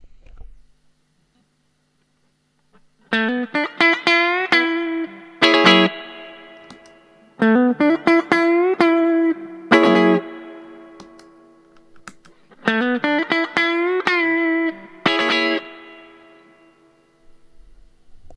ついでに、前回コンプレッサーの比較で音色の差が良く分からんという意見が多かったので、パッシブピックアップのAXISを使って比較しました。
OFF、OrangeSqueezer、Keeleyの順にひいています。PUセレクターはセンター（ミックス）。こうやって聴くとOrangeSqueezerもどきは高音に比べて中低音が強調され、甘い音色になり、Keeleyは音色はそのまま圧縮される感じですね。